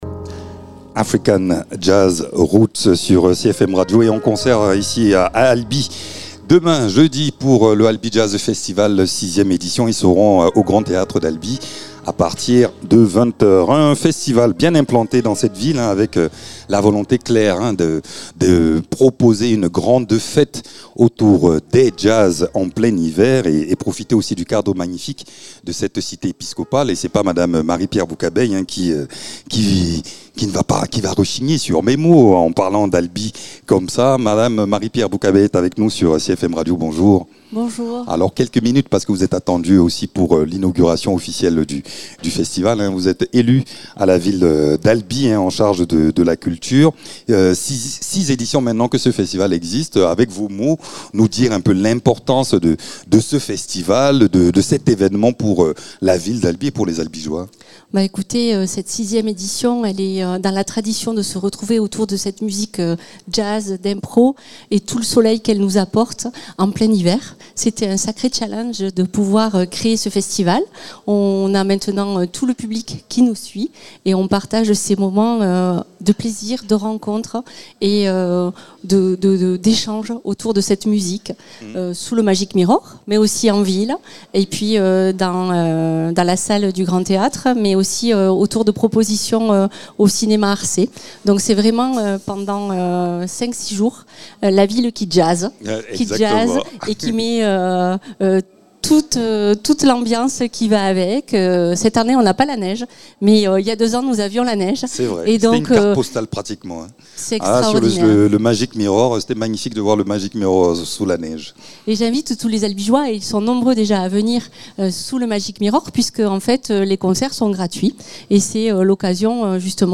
Invité(s) : Marie-Pierre Boucabeille, adjointe au maire déléguée à la culture.